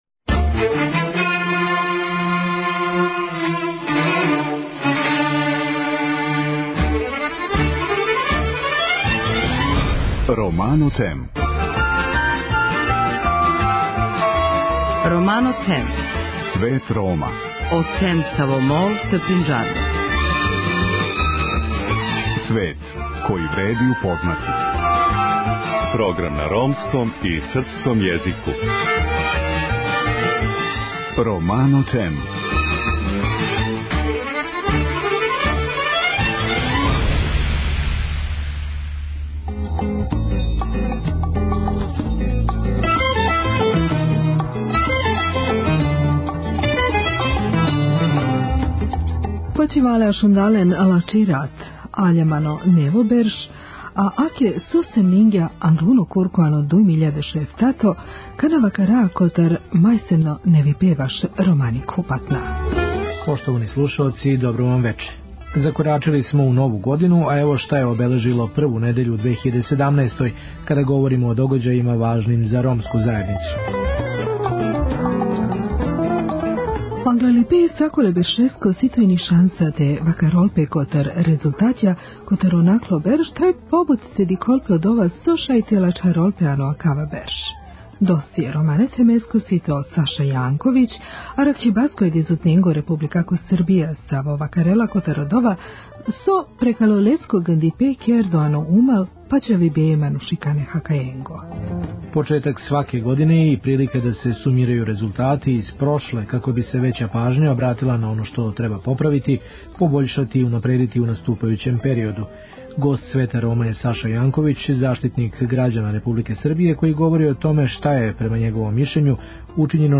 Гост Света Рома био је Саша Јанковић, заштитник грађана Републике Србије који говори о томе шта је, по његовом мишљењу, учињено на пољу поштовања људских права Рома.
Слушамо део репортаже која осликава њихов живот унутар муслиманске четврти Јерусалима у мешовитом насељу Баб ал-Хута.